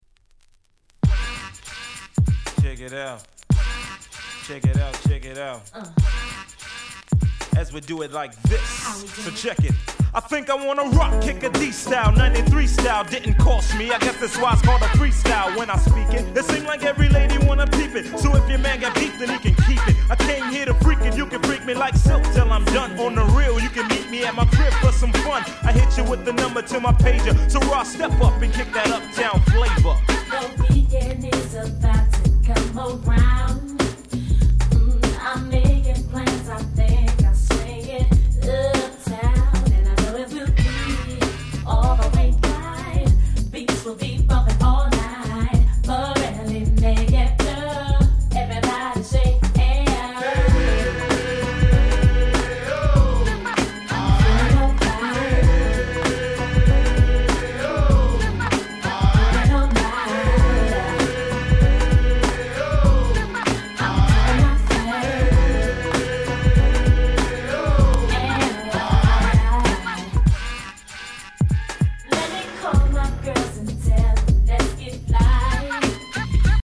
94' Party Hip Hop Classic !!
サビの「エ〜ヨーゥ、アーイ！」で大合唱必至の大変Party映えする1曲。
キャッチーでメロディアスなTrack故にR&Bファンからも非常に人気の高かった1枚です。